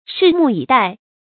注音：ㄕㄧˋ ㄇㄨˋ ㄧˇ ㄉㄞˋ
拭目以待的讀法